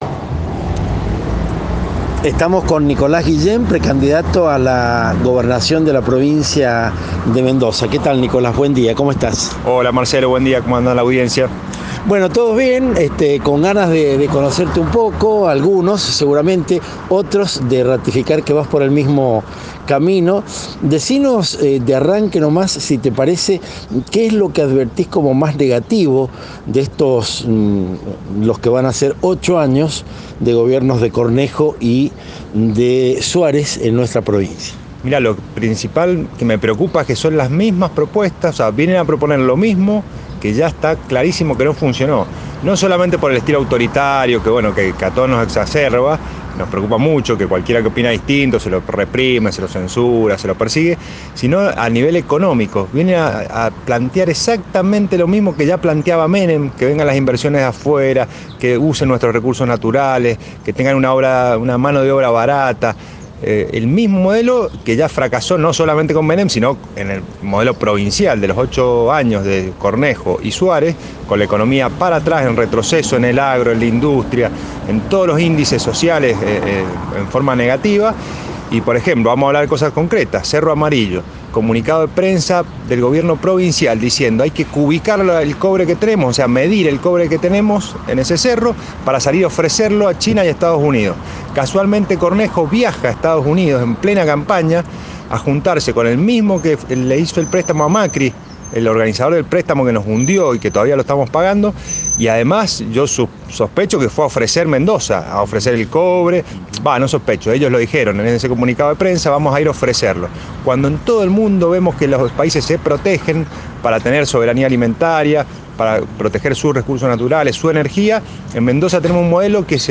No hay frases afectadas en su repertorio discursivo. Sin alzar la voz, te va sembrando la charla con las principales ideas por las que genera política desde hace años.
Entrevista